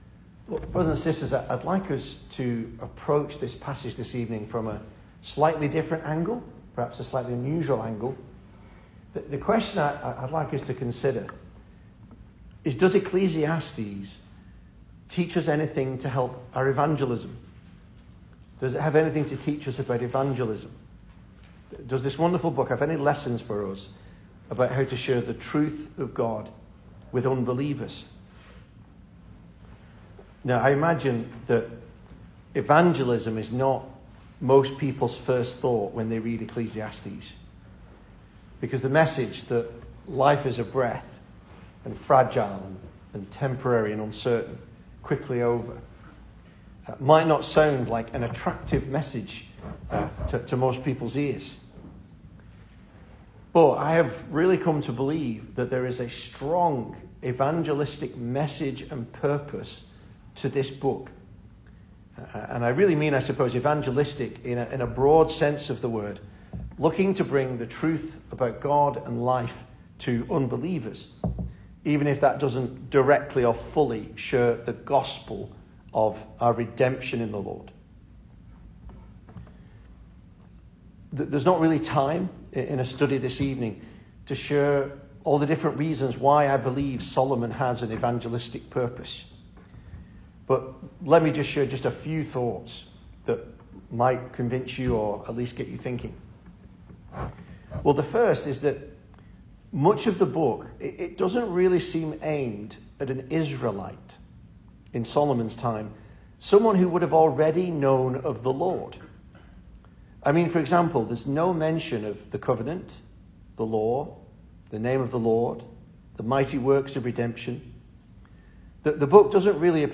2026 Service Type: Weekday Evening Speaker